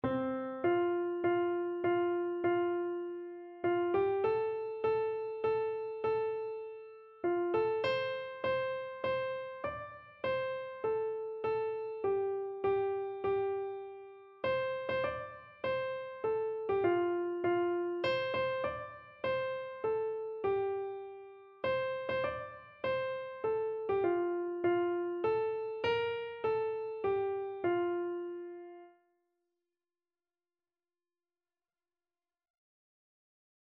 Free Sheet music for Keyboard (Melody and Chords)
F major (Sounding Pitch) (View more F major Music for Keyboard )
3/4 (View more 3/4 Music)
Keyboard  (View more Intermediate Keyboard Music)
Classical (View more Classical Keyboard Music)